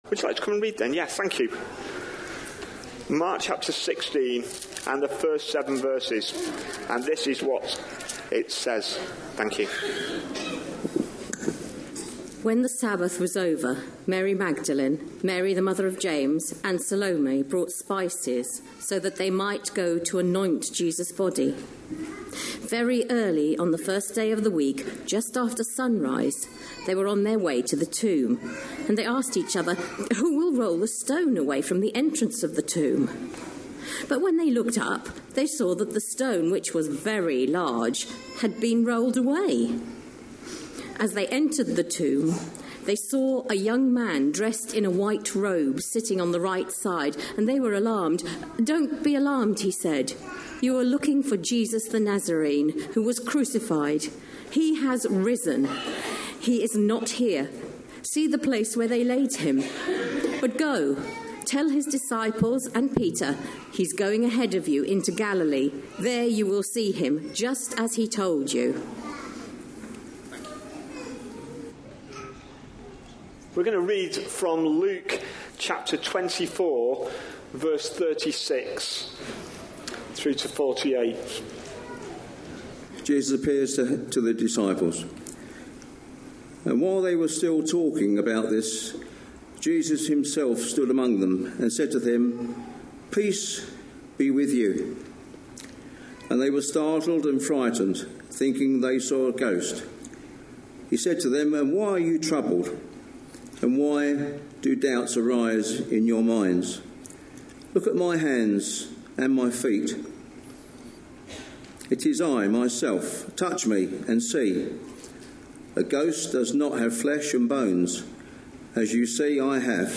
A sermon preached on 21st April, 2019, as part of our Mark. series.